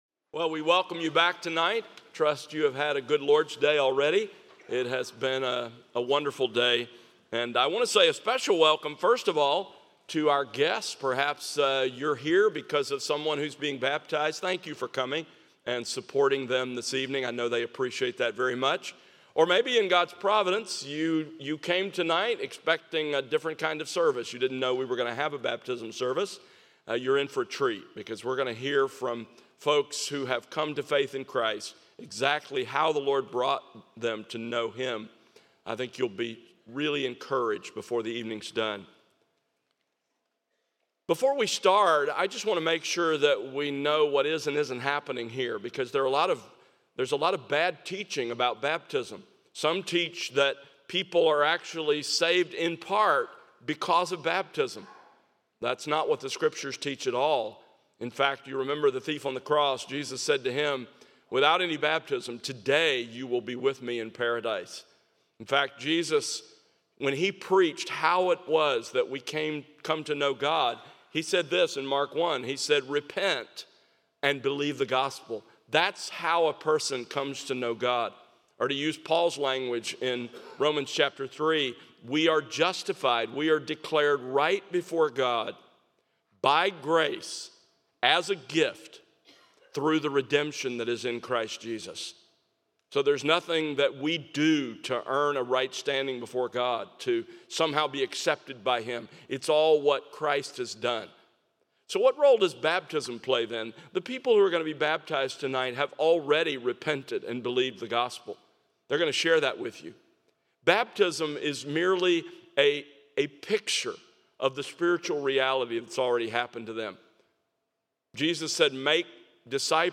Baptisms